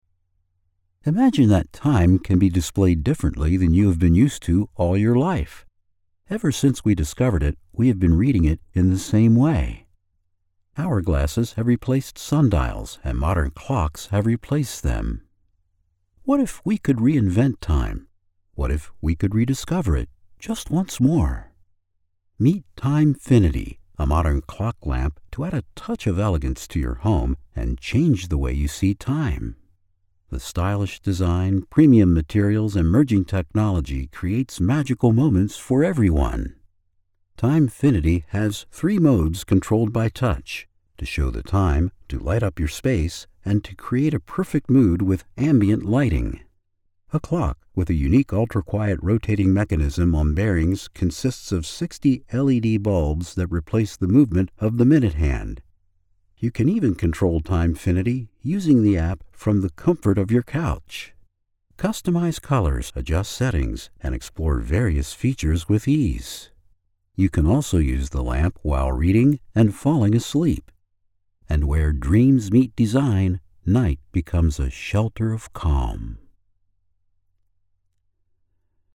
Unternehmensvideos
iMac, Neumann TLM 103-Mikrofon, Steinberg UR 22-Schnittstelle, Adobe Audition.
4' x 6' x 7' Studio-Innenraum. Durchgehend mit Teppichboden ausgelegt. Doppelt verkleidet. Durchschnittlicher Geräuschpegel -55 dB.
Im mittleren Alter
Senior